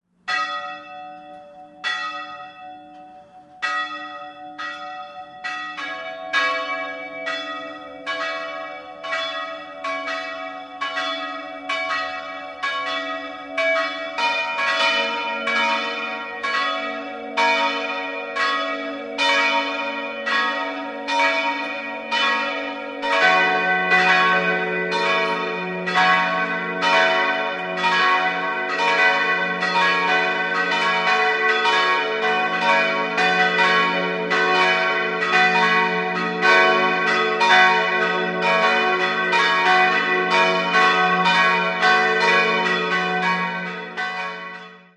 4-stimmiges SalveRegina-Geläute: ges'-b'-des''-es'' Die beiden großen Glocke wurden 1951 von Rudolf Perner in Passau gegossen, wobei Glocke 2 im Jahr 1964 durch eine neue, ebenfalls von Perner, ersetzt wurde. Glocke 3 stammt aus dem Anfang des 14. Jahrhunderts und Glocke 4 wurde 1630 gegossen.